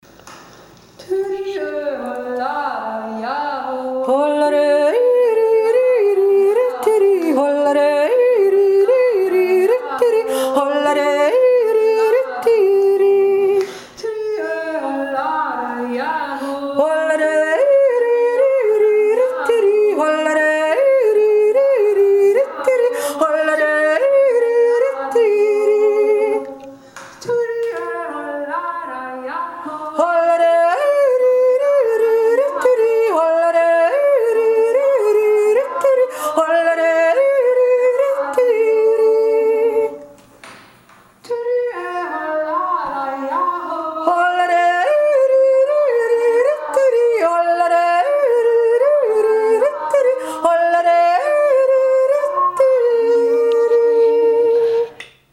2. seconda voce lenta